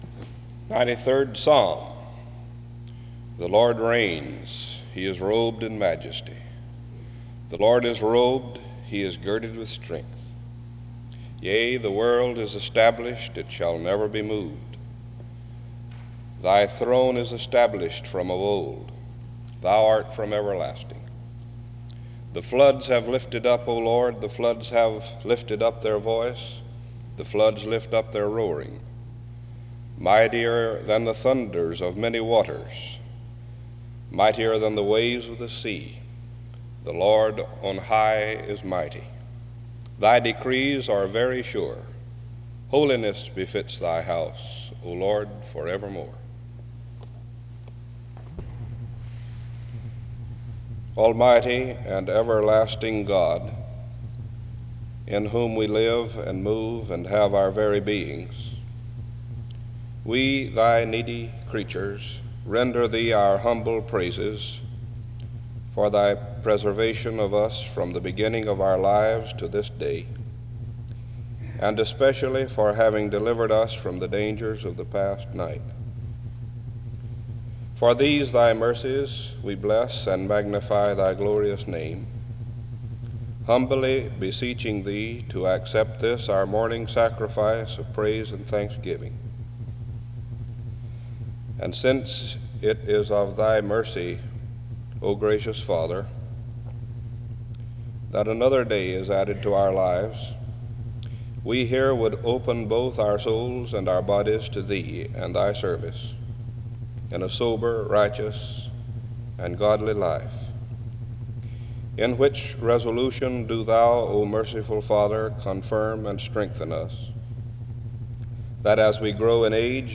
[Audio cuts out before message ends.]
In Collection: SEBTS Chapel and Special Event Recordings SEBTS Chapel and Special Event Recordings